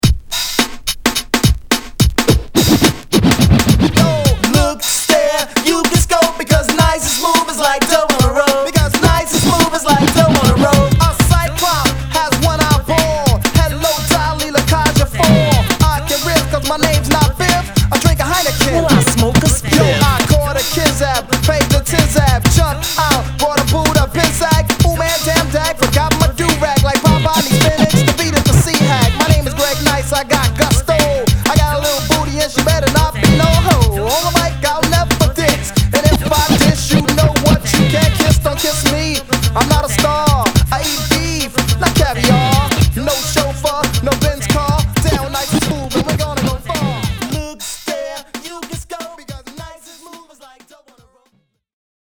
ホーム HIP HOP 80's 12' & LP G